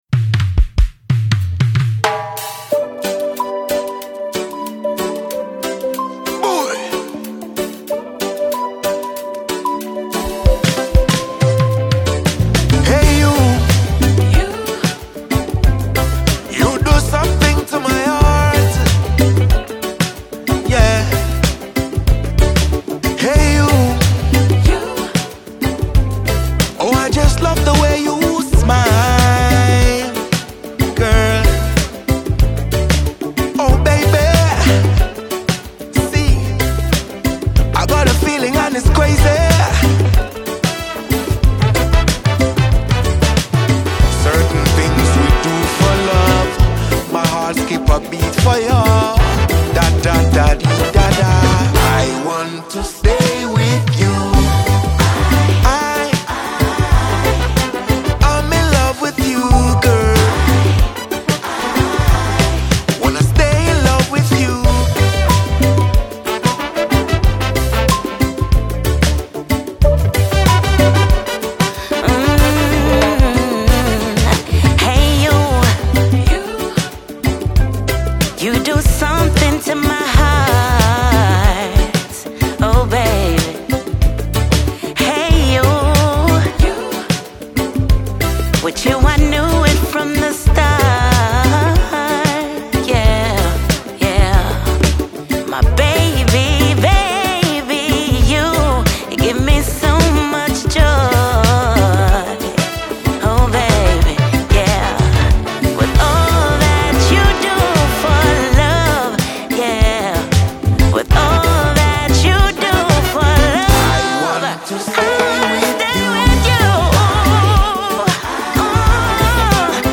Ghanaian reggae dancehall sensational